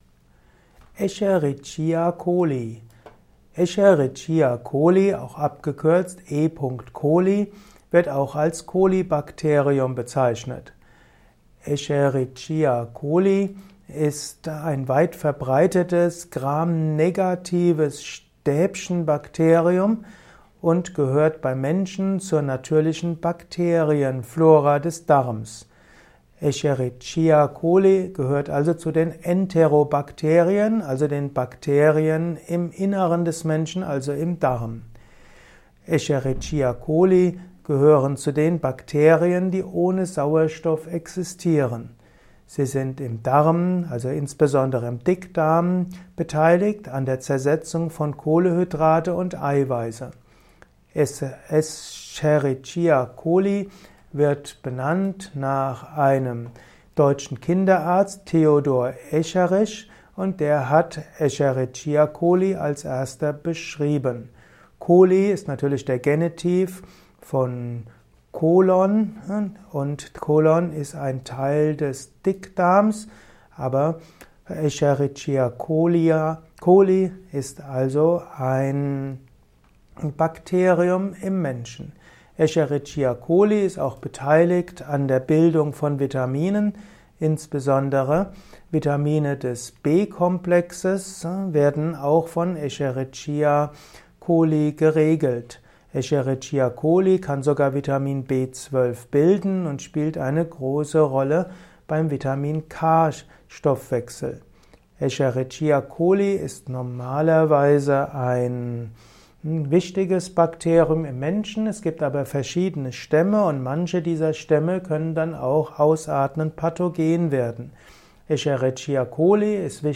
Ein Kurzvortrag über das E.Coli-Bakterium